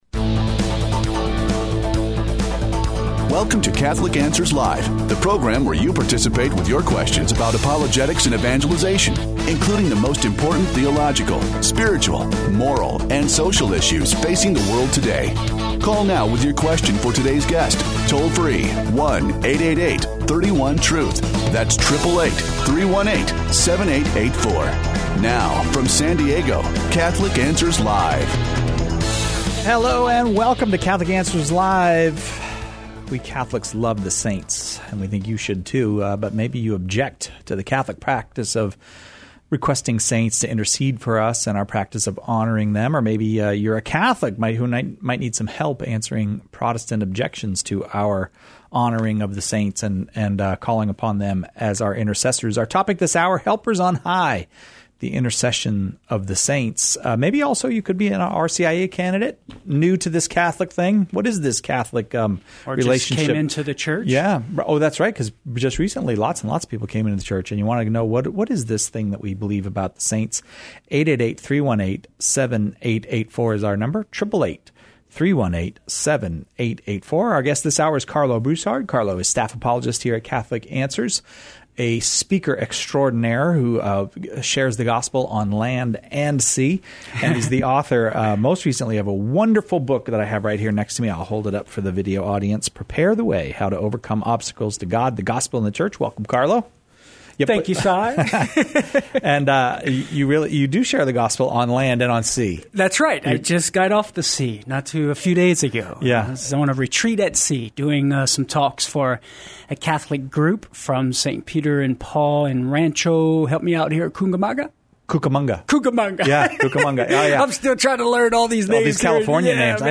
takes caller questions on the role of saints in Catholic life. Questions Covered: 15:08 - How do the saints hear us? Are they always listening? 21:40 - Are holy men outside of the tradition of the church saints?